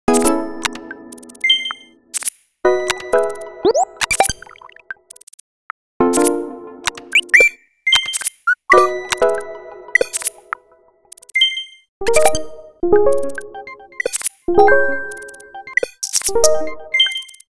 Sound Design for button presses on the Nintendo Switch.
switch-sounds_mixdown.wav